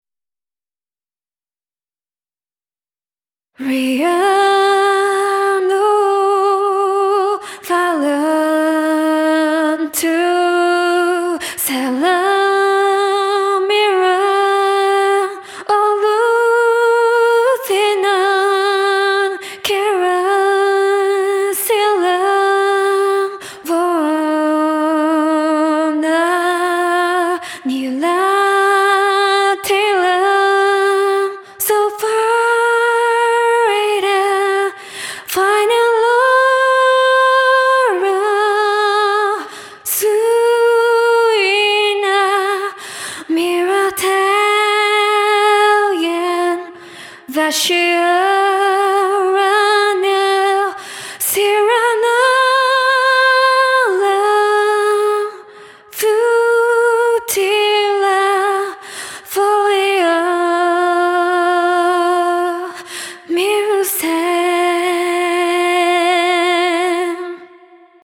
ボーカルは Synthsizer V2  宮舞モカで、歌詞は LLM で作った造語です。
この曲をボーカルとオケを別々に書き出しました。
<ボーカル>
sorrowmusic_vocal.mp3